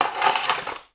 SPADE_Scrape_Asphalt_RR2_mono.wav